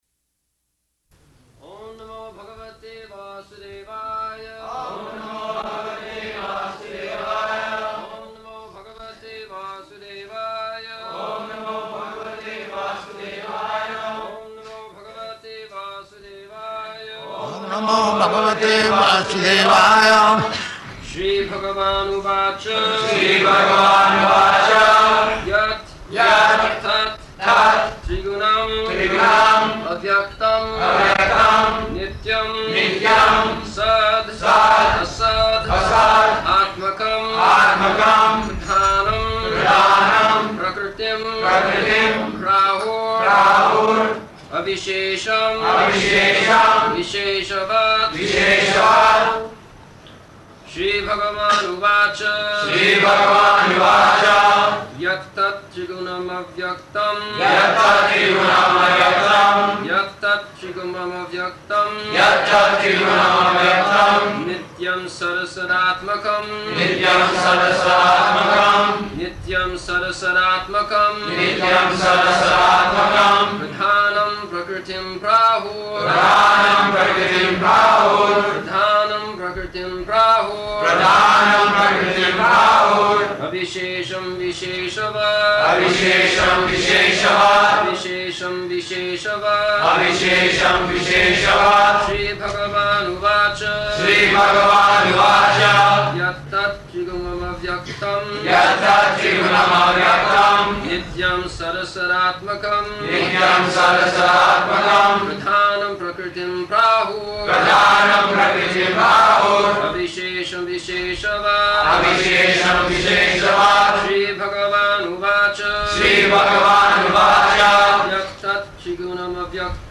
December 22nd 1974 Location: Bombay Audio file
[Prabhupāda and devotees repeat] [leads chanting of verse, etc.]